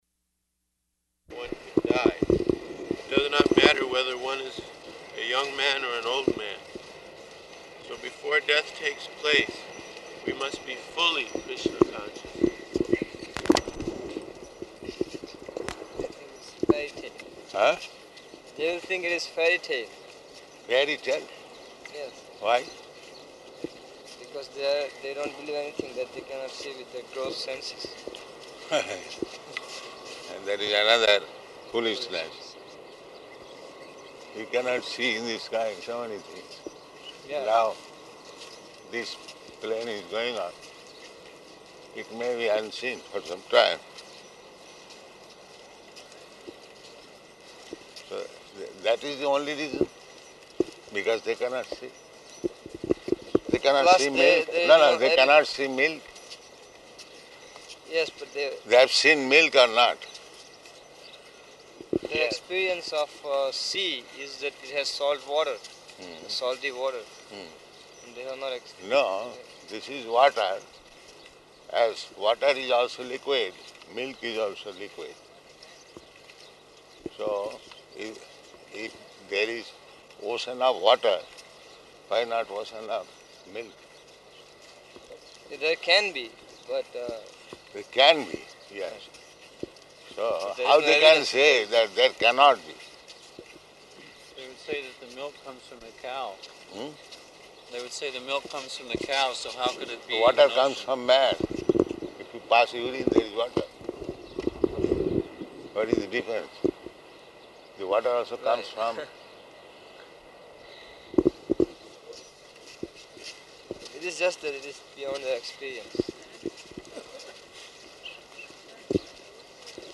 -- Type: Walk Dated: April 7th 1974 Location: Bombay Audio file